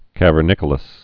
(kăvər-nĭkə-ləs)